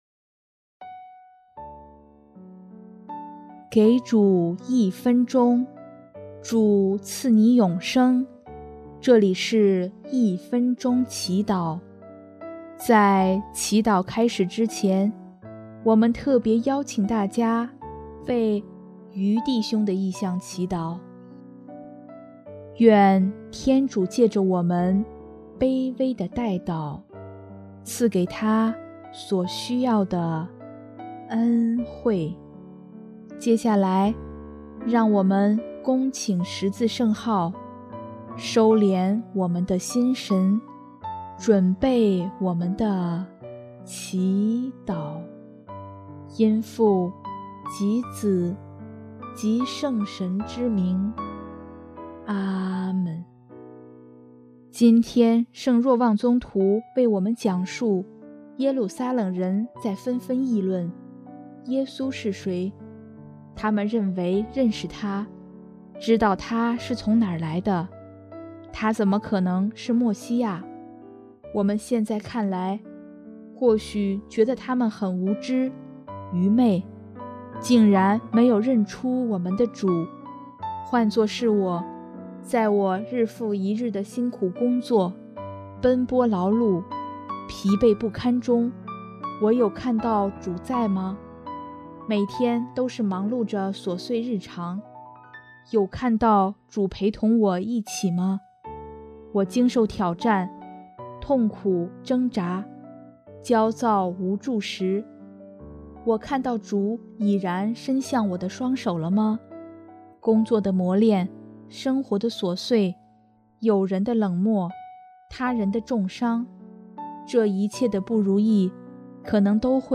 音乐：第四届华语圣歌大赛参赛歌曲《天父的爱》